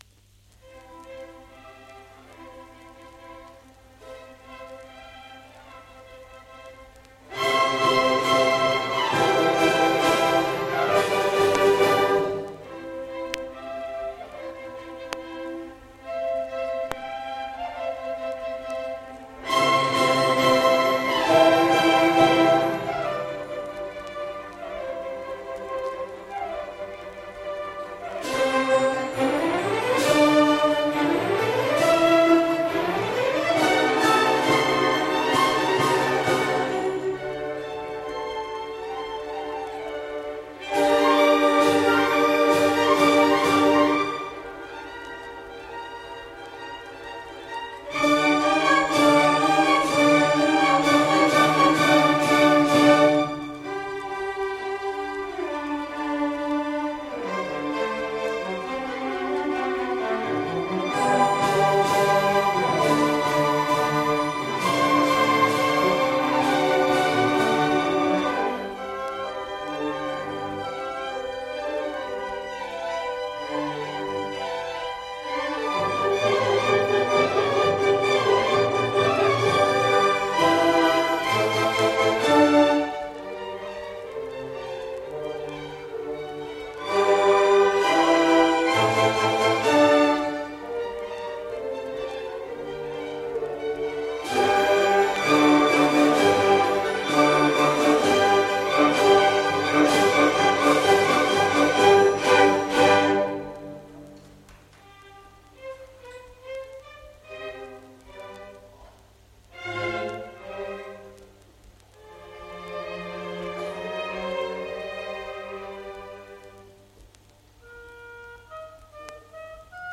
Youth Orchestra